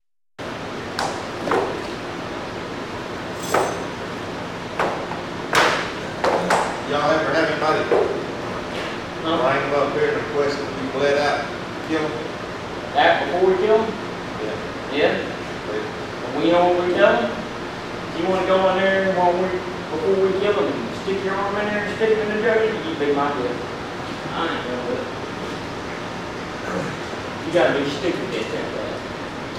Hearing only the sound of the slaughter leaves the mind to fill in almost everything, biased with our own beliefs and preconceptions.
Field Recording Series by Gruenrekorder